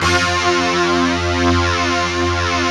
Index of /90_sSampleCDs/Optical Media International - Sonic Images Library/SI1_DistortGuitr/SI1_DstGtrWalls